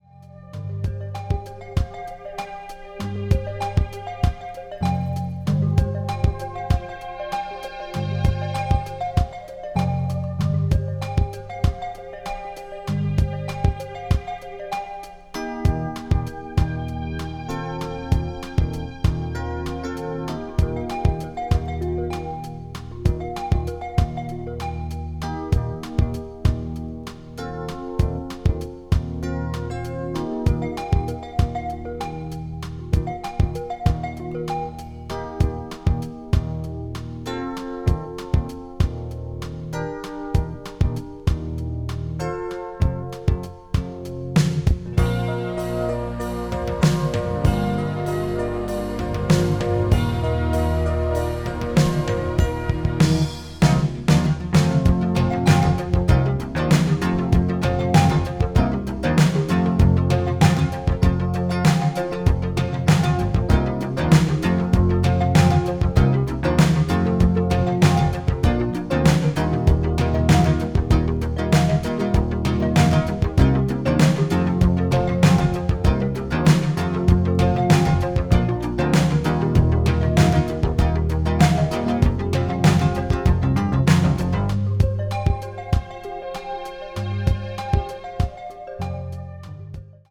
ambient   contemporary jazz   modern classical   new age